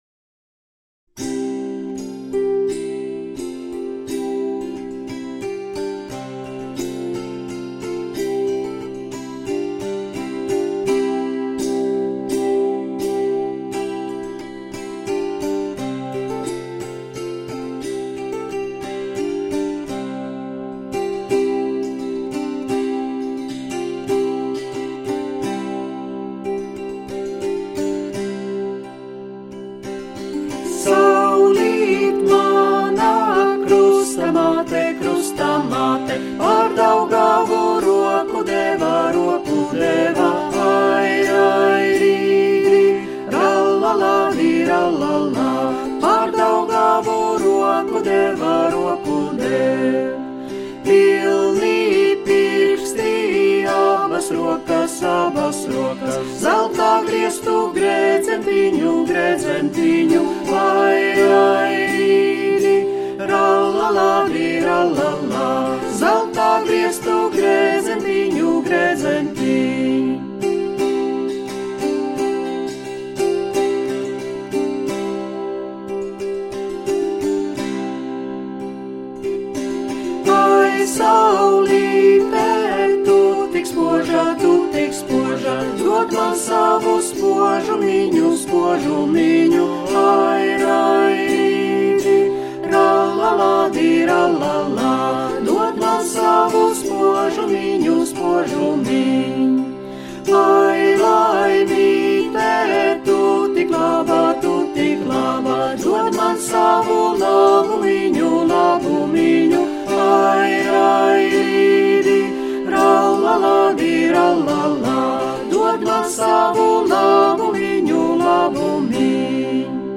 Grāmatai pielikumā ievietots latviešu tautasdziesmu mūzikas disks, kurā tautasdziesmas izpilda dažādas Latvijas folkloras grupas un izpildītāji.